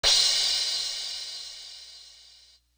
Crashes & Cymbals
Latin Thug Crash.wav